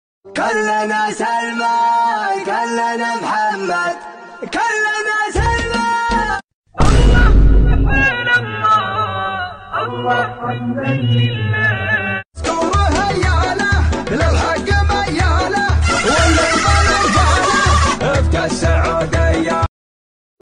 arab song meme Meme Sound Effect
arab song meme.mp3